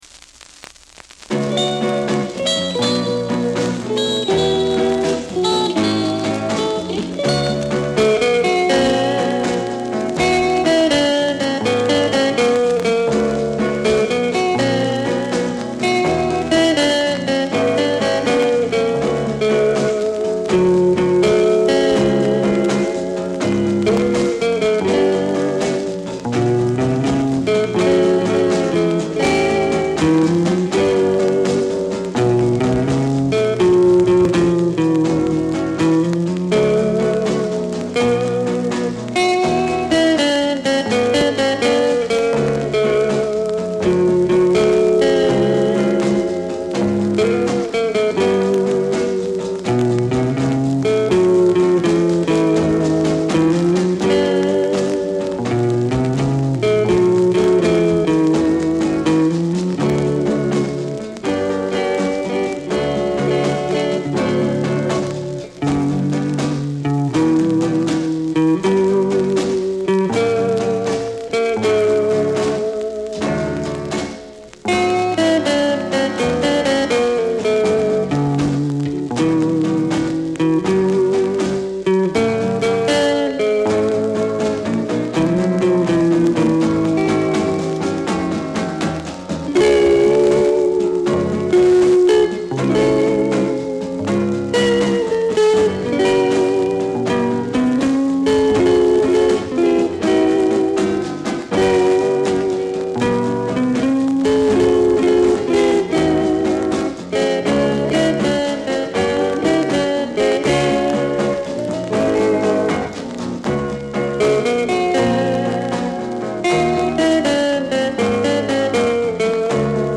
instrumental surf rock band
rhythm guitar
drums